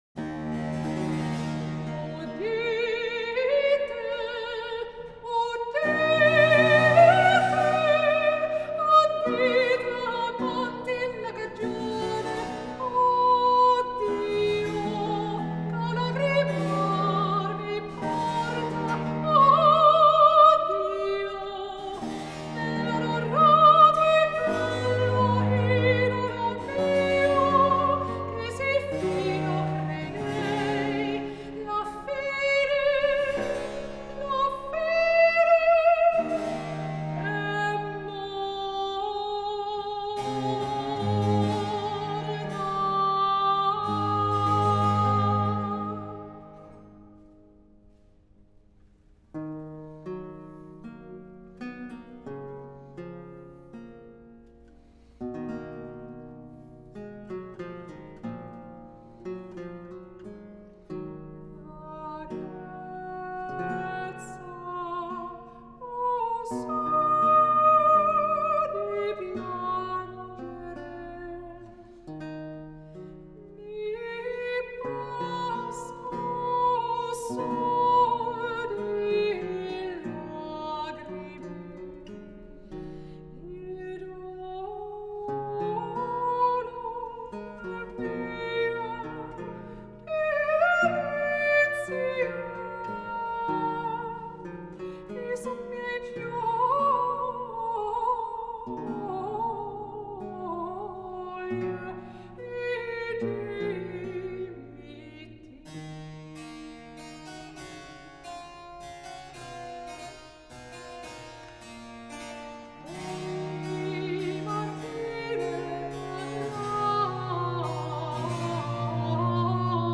theorbe
cello
klavecimbel